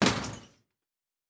Metal Foley Impact 1.wav